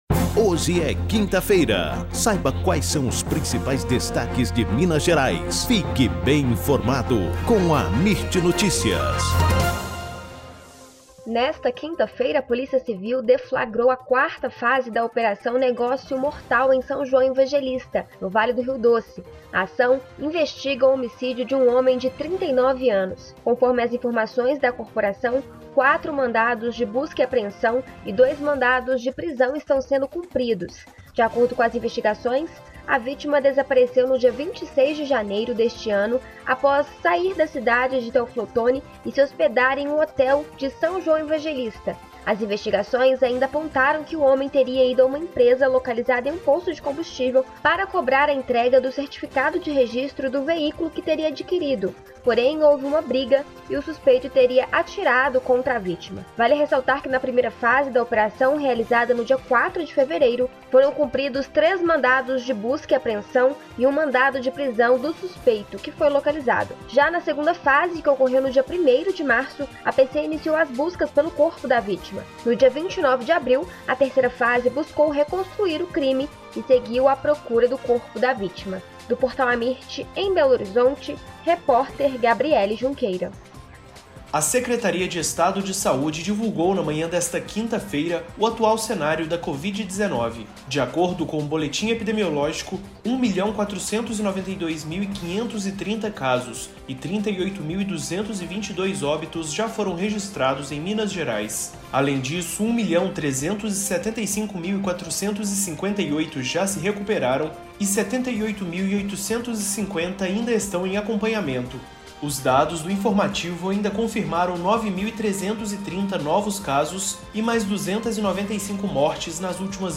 AMIRT Amirt Notícias Destaque Gerais Notícias em áudio Rádio e TelevisãoThe estimated reading time is less than a minute